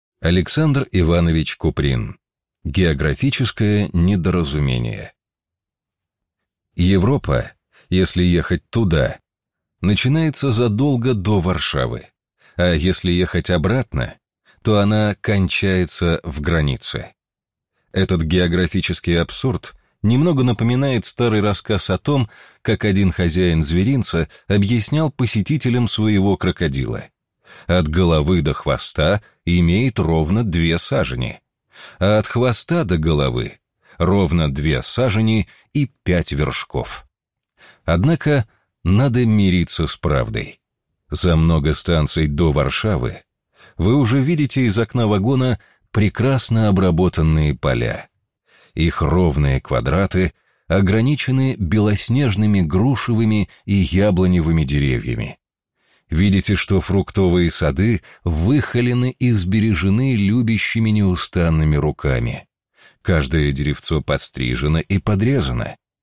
Аудиокнига Географическое недоразумение | Библиотека аудиокниг